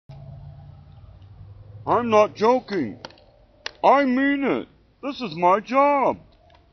Donald Sutherland in Animal House is still using the voice he had while he was holding his talking voice up.